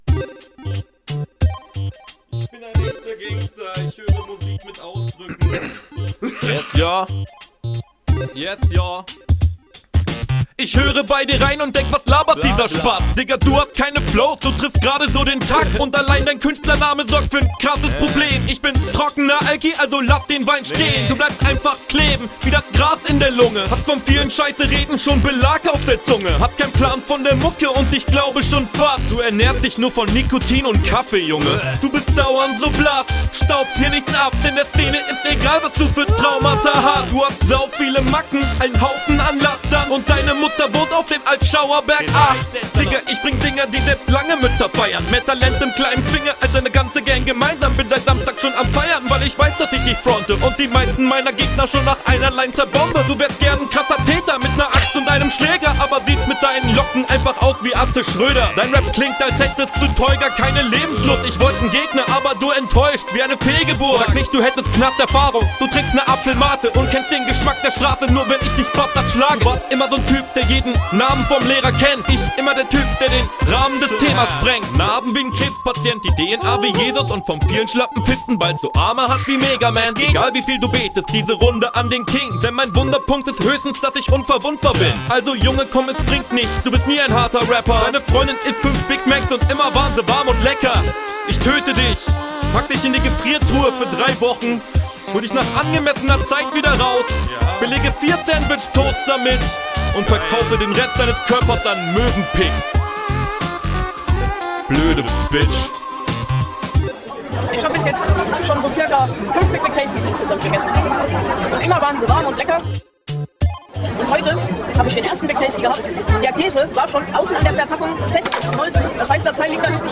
Die Stimme finde ich stark und der Flow ist in Ordnung.